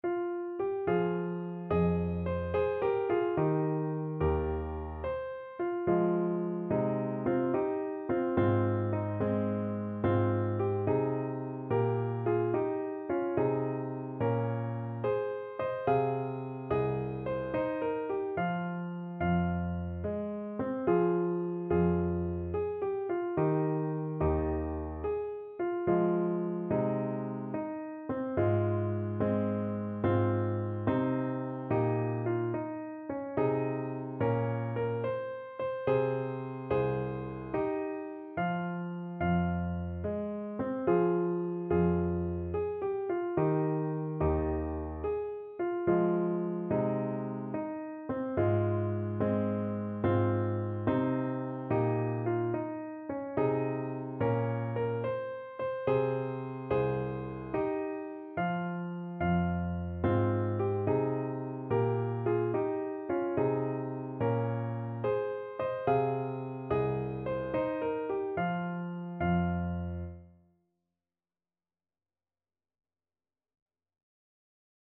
9/8 (View more 9/8 Music)
Gently .=c.72
Traditional (View more Traditional French Horn Music)